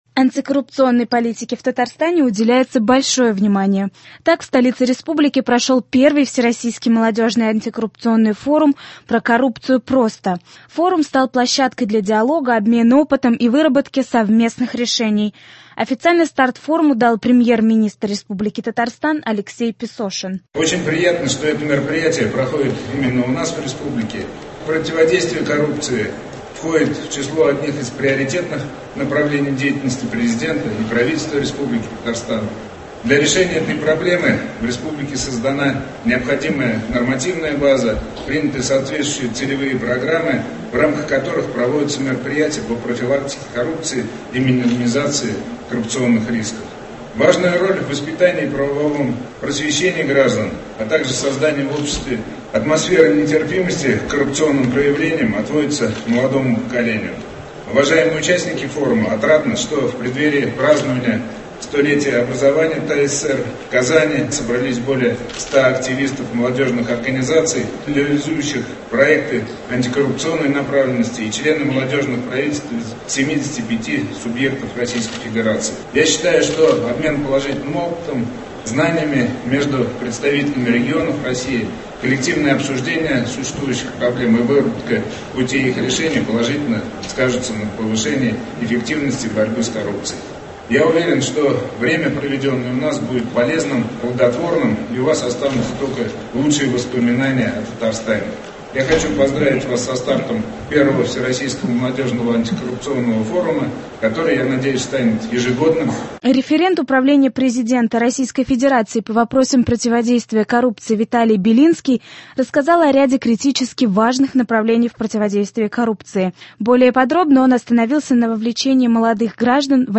Репортаж с первого Всероссийского антикоррупционного форума в Казани.